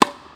serve_smash_01.wav